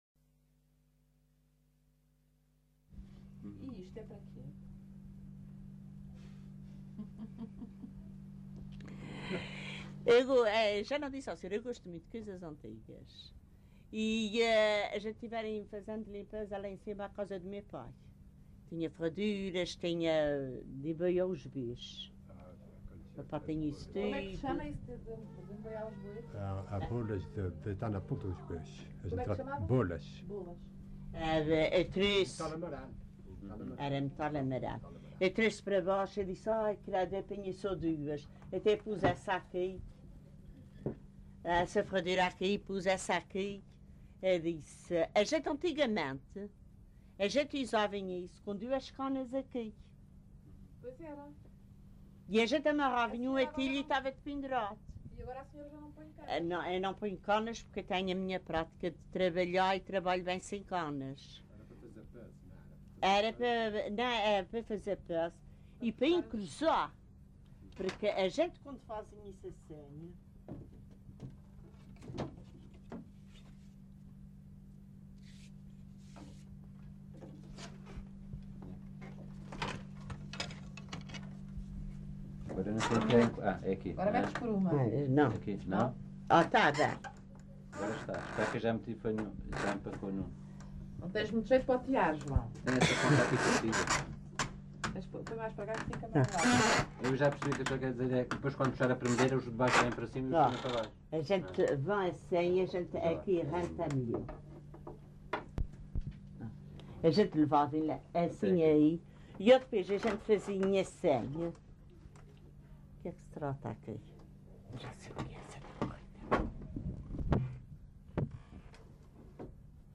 LocalidadePonta Garça (Vila Franca do Campo, Ponta Delgada)